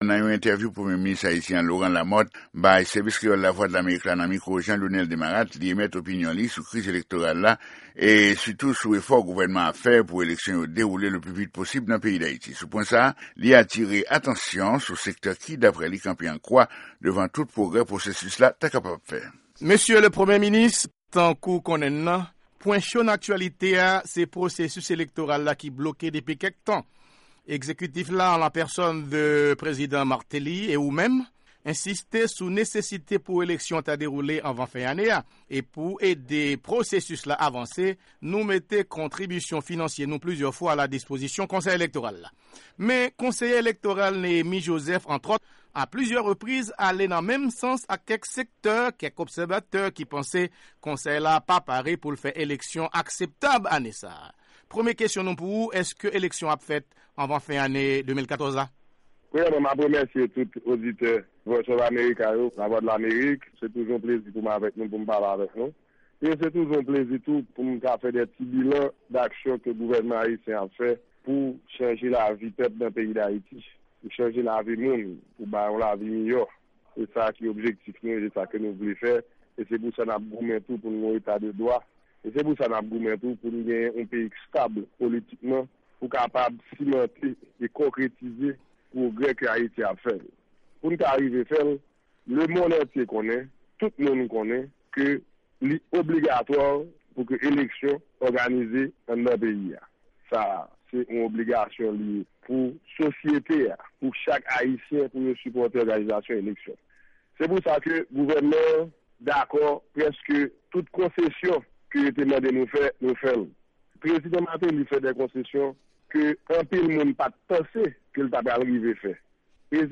EntèvyouPremye Minis Lamothe ak Lavwadlamerik - 4 septanm 2014